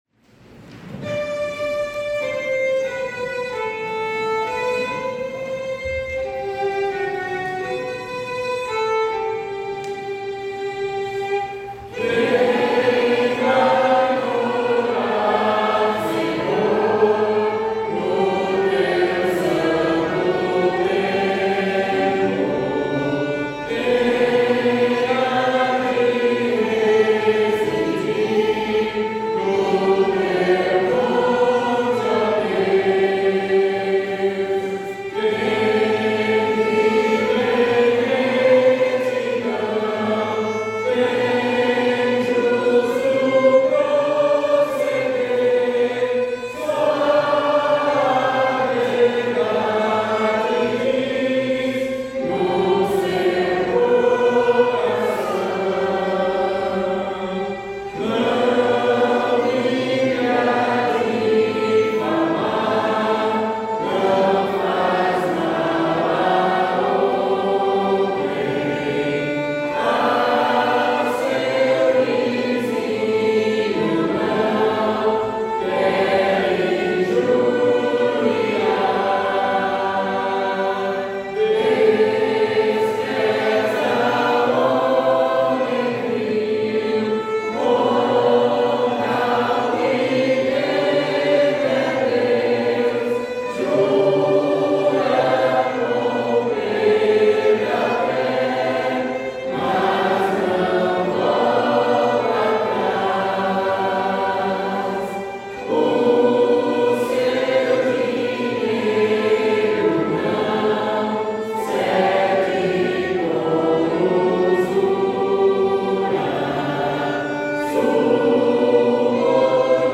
salmo_15B_cantado.mp3